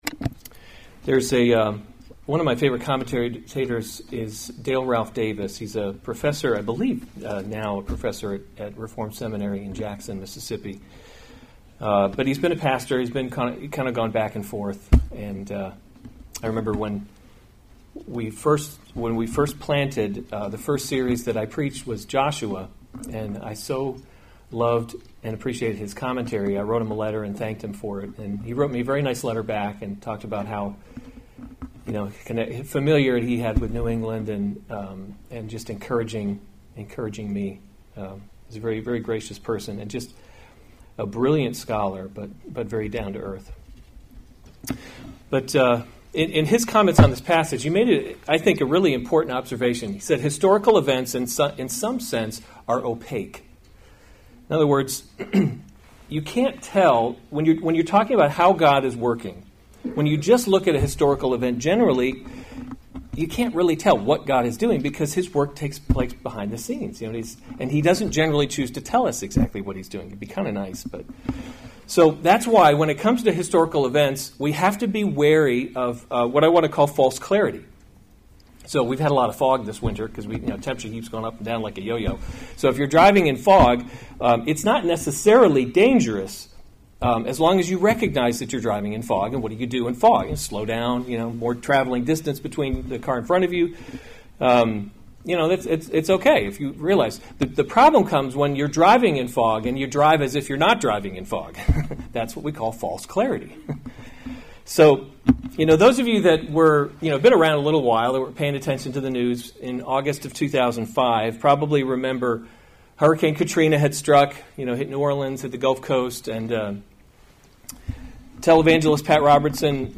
February 16, 2019 1 Kings – Leadership in a Broken World series Weekly Sunday Service Save/Download this sermon 1 Kings 11:14-43 Other sermons from 1 Kings 14 And the Lord raised […]